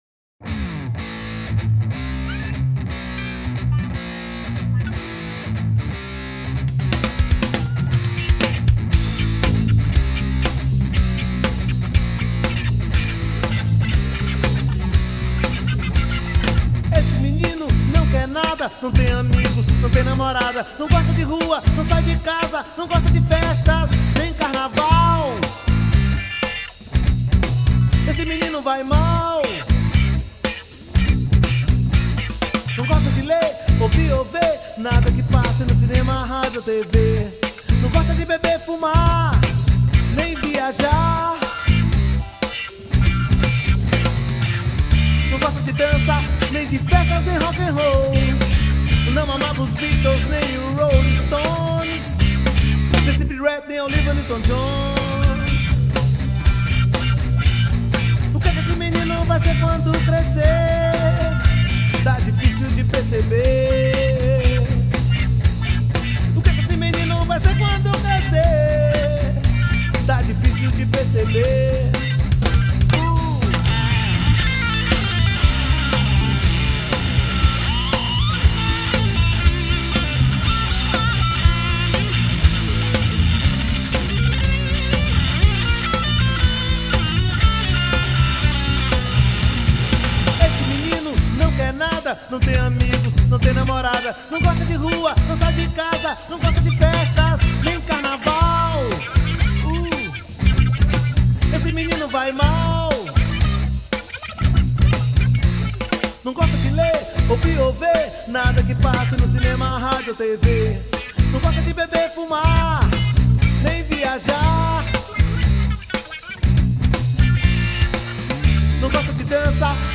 2617   03:14:00   Faixa: 9    Reggae